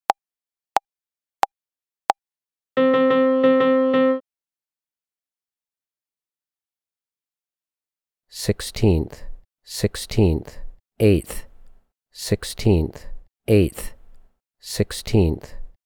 • Level 2: Dotted Quarter, Quarter and Eighth Rhythms in 2/4.
Find examples below for each level of the voice answer MP3s:
Rhy_ET_L2_90BPM-1.mp3